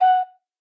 flute.ogg